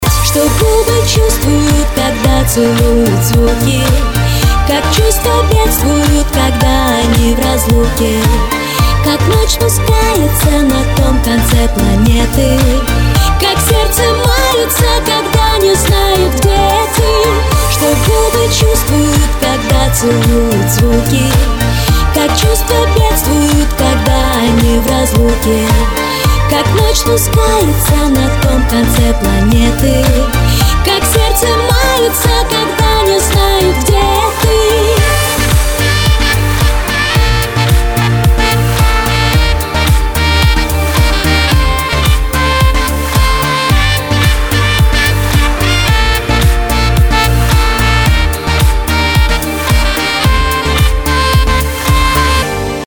романтичные Саксофон ритмичные